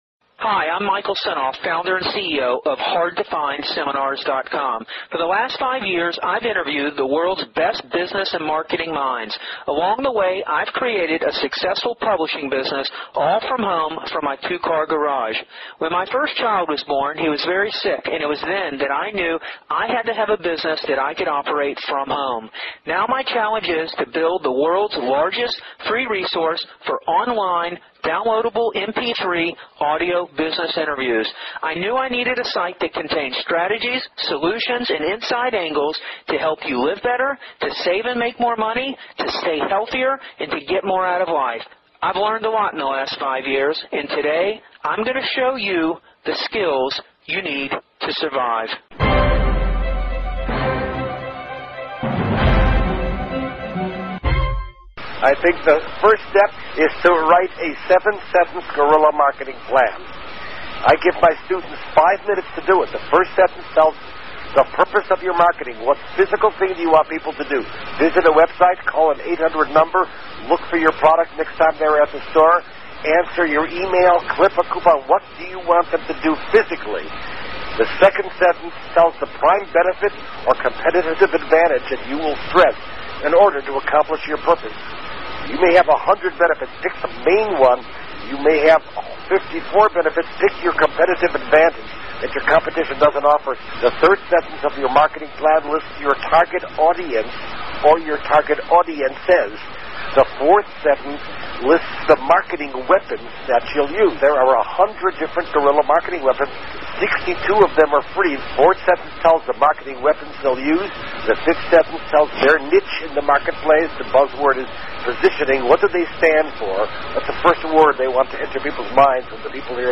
Jay Conrad Levenson Interview part 1